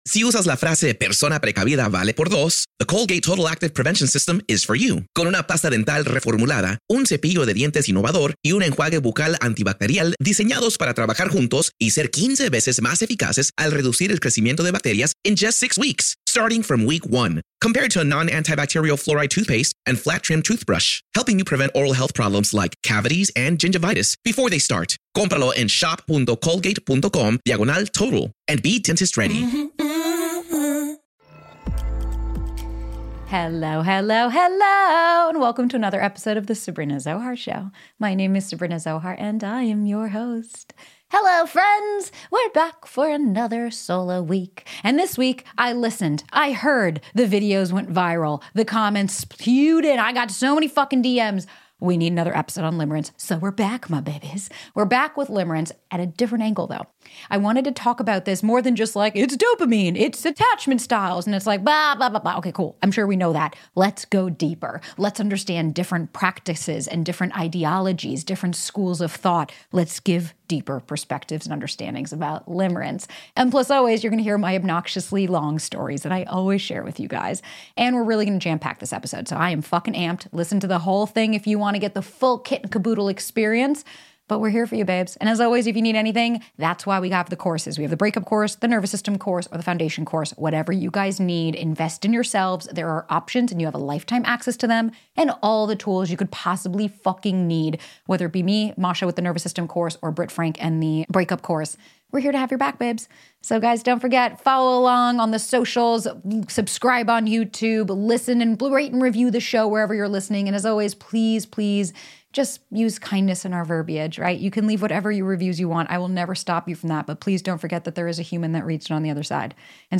In this solo episode